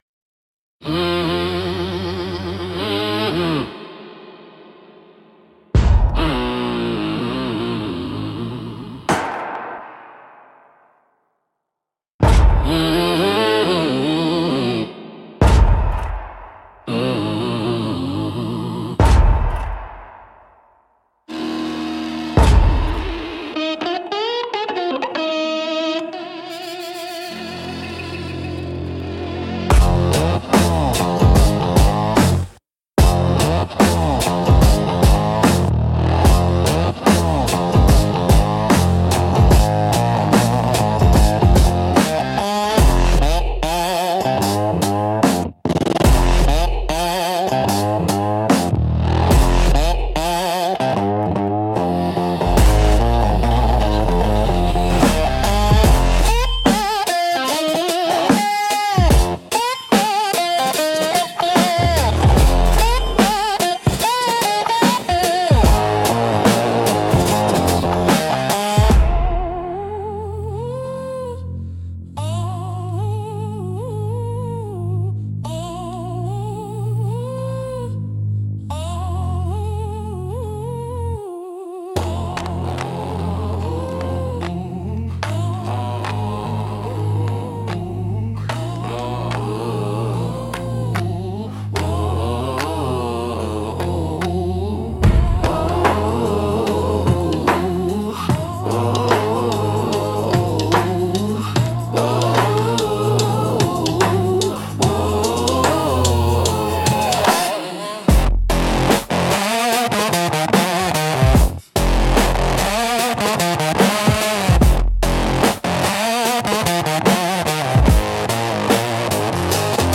Instrumental - Iron in the Water -2.29